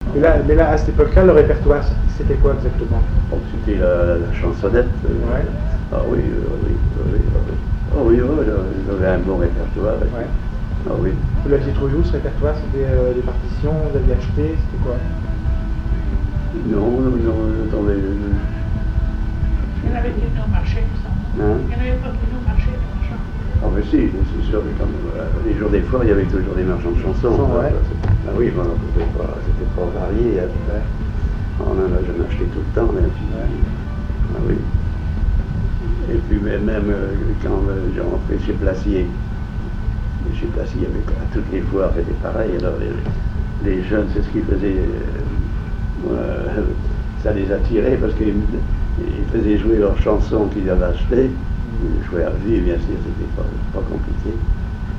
musicien(s) ; accordéon(s), accordéoniste
Catégorie Témoignage